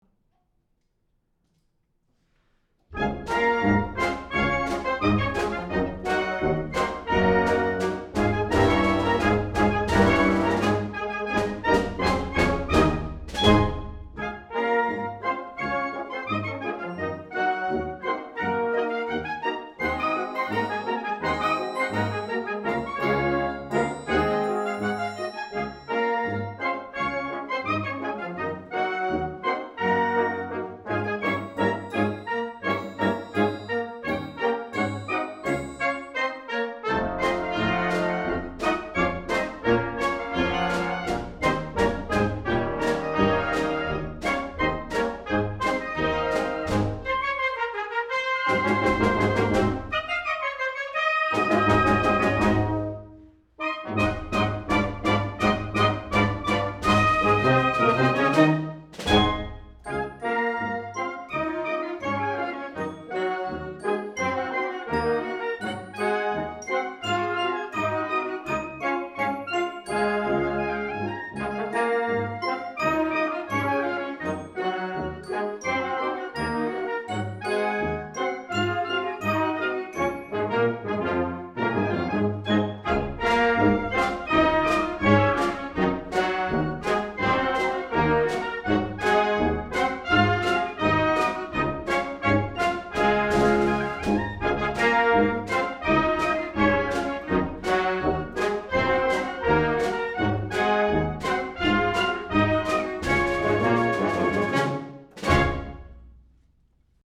Db Piccolo
C Flute
Bassoon
Bb Clarinet
Alto Saxophone
Bb Cornet
Trombone
Euphonium
Tuba
Percussion:
microphones, with an Edirol UA-25 A/D resulting in 24 bit 96KHz master